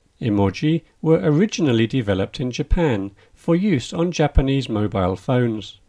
DICTATION 6